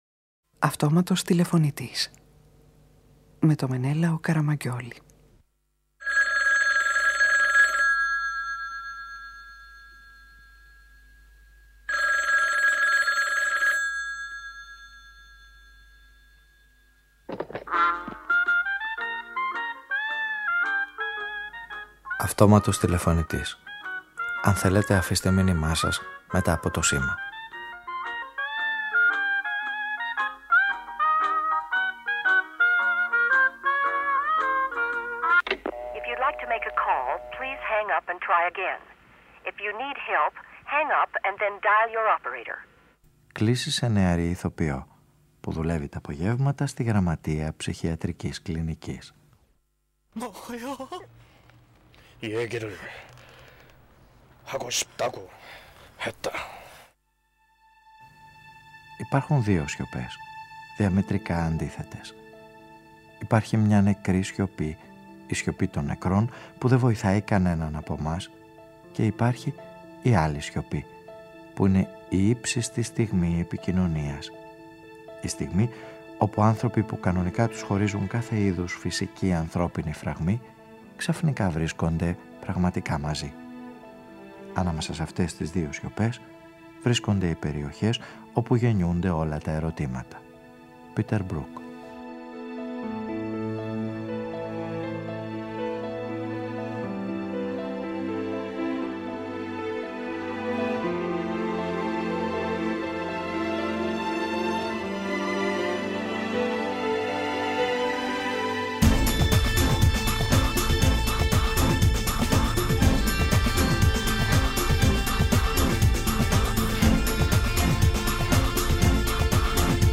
Η ηρωίδα αυτής της ραδιοφωνικής ταινίας είναι μια νεαρή ηθοποιός που αντιστέκεται στην τηλεόραση και μέχρι να της έρθουν οι ρόλοι που ονειρεύεται δουλεύει στη γραμματεία μιας ψυχιατρικής κλινικής. Οι οδηγίες του Πήτερ Μπρουκ και όσα ζει κάθε μέρα στη δουλειά της την οδηγούν στα νέα μονοπάτια της υποκριτικής (όπως τον αυτοσχεδιασμό) που διεκδικεί για να προσεγγίσει το αληθινό θέατρο και τον ουσιαστικό κινηματογράφο; Τι μπορεί να πετύχει κανείς ανάμεσα σε δύο σιωπές και στο τέλος αυτής της ιστορίας θα ξεκαθαριστεί ποιές είναι αυτές οι δύο;